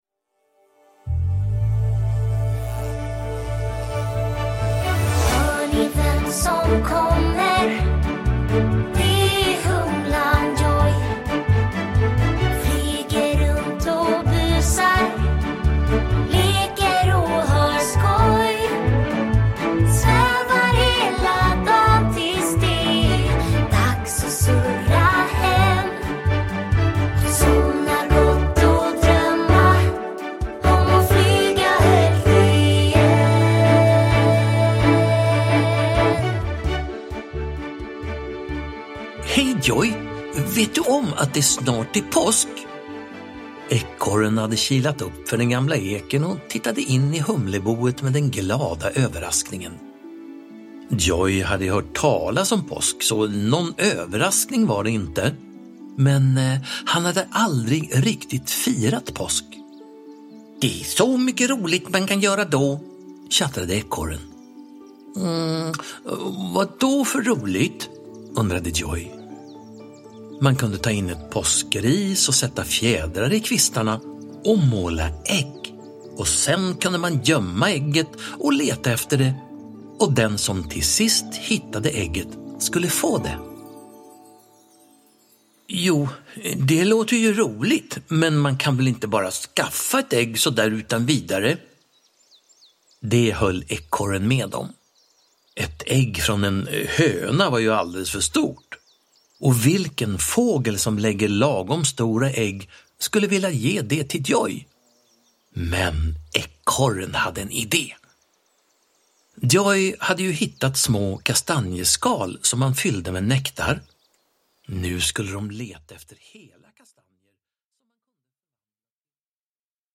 Uppläsare: Staffan Götestam
Ljudbok